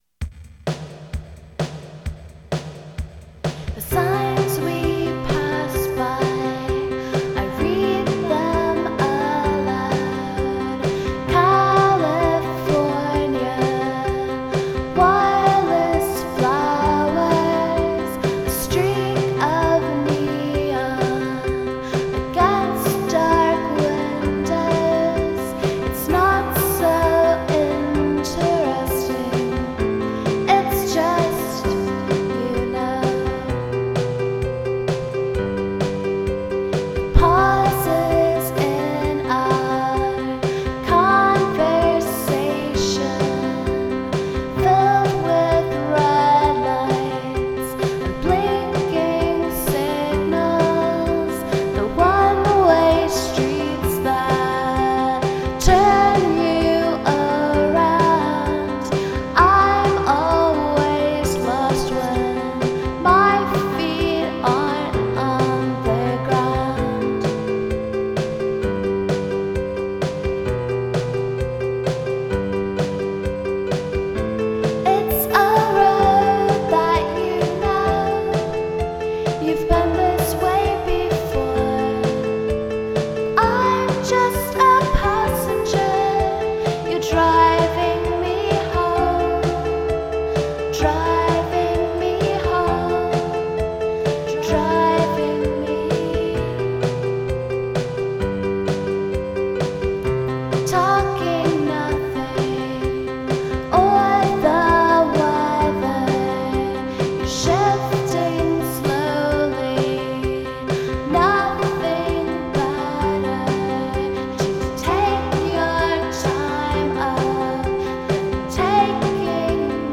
a-a-b-a-b-a-c-b-b
c major, i think
this is the laziest recording ever made, just the "us rock" yamaha beat and one piano track, and two vocal takes with some accidental harmonies, hell of reverb, and absolutely no dynamics. hehehe!